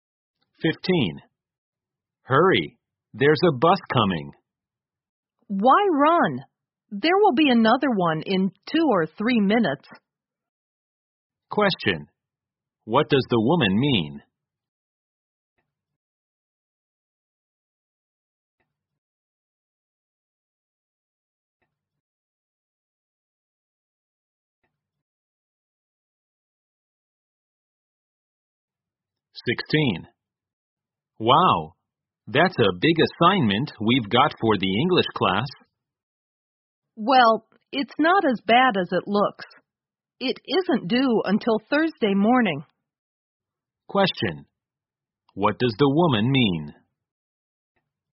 在线英语听力室003的听力文件下载,英语四级听力-短对话-在线英语听力室